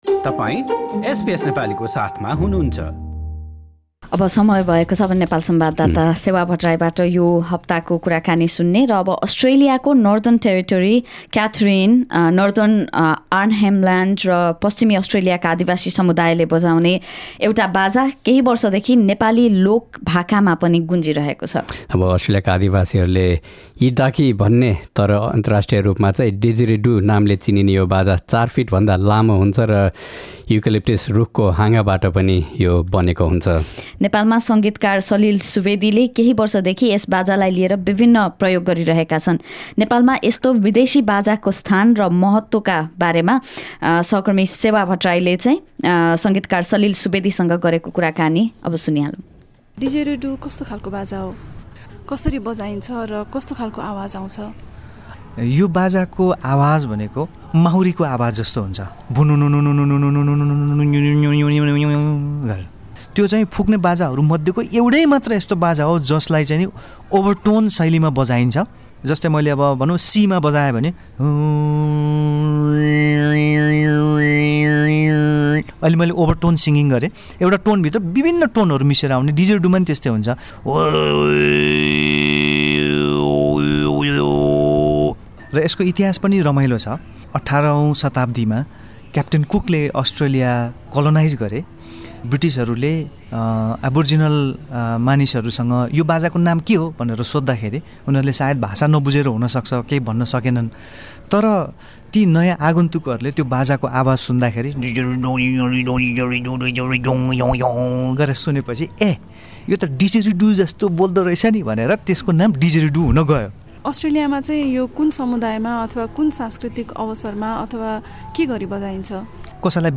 एसबीएस नेपाली पोडकास्ट